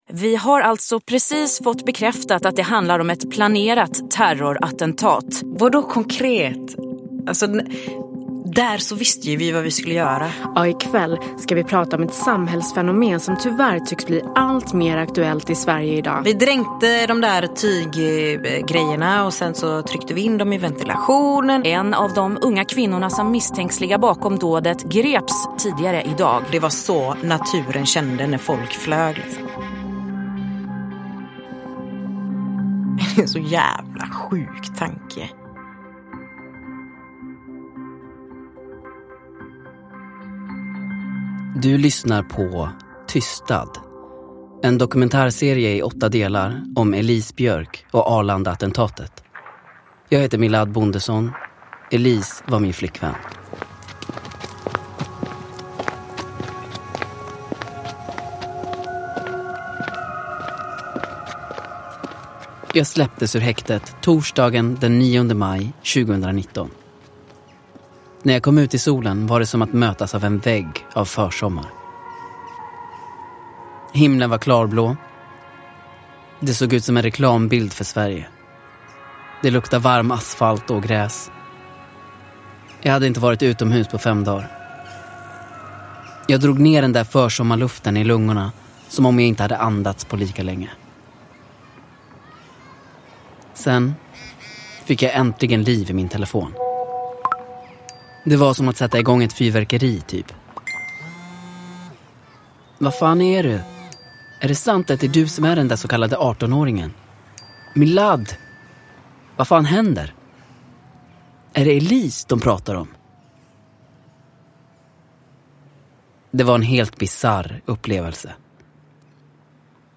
Tystad - 6 – Ljudbok – Laddas ner